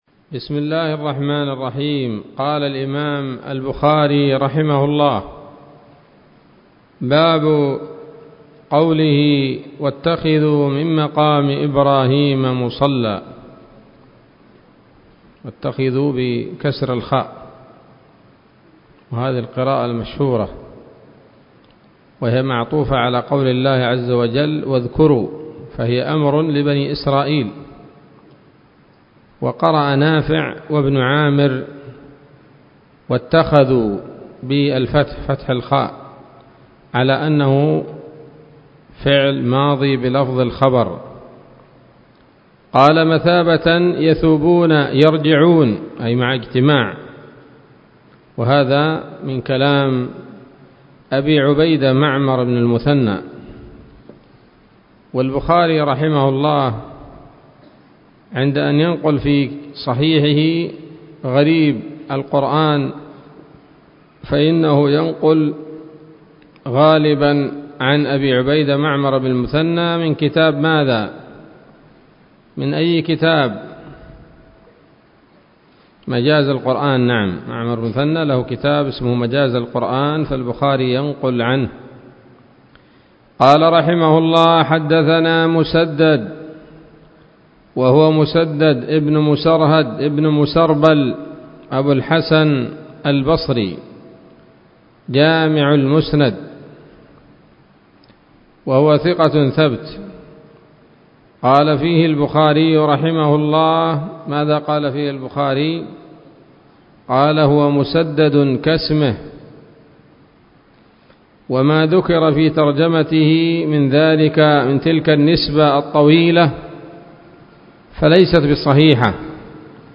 الدرس العاشر من كتاب التفسير من صحيح الإمام البخاري